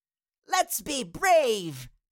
Cartoon Little Child, Voice, Lets Be Brave Sound Effect Download | Gfx Sounds
Cartoon-little-child-voice-lets-be-brave.mp3